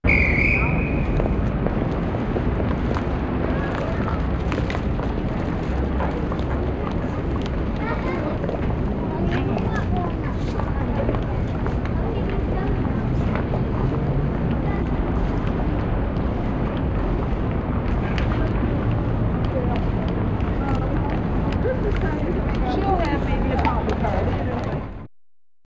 Шум перекрестка с пешеходным переходом